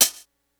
Closed Hats
06 hi hat 3 hit.wav